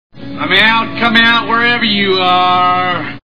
Cape Fear Movie Sound Bites